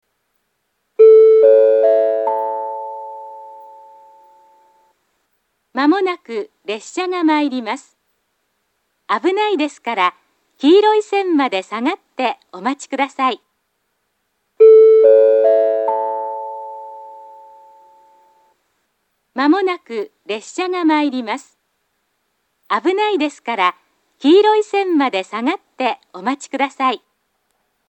２番線上り接近放送